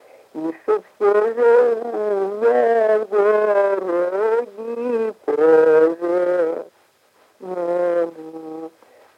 Произнесение слова меня как мня